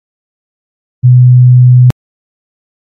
(Pour écouter les sons à différentes fréquences ci-dessous, il suffit de cliquer sur celui que vous souhaitez entendre)
125 Hz] [250 Hz] [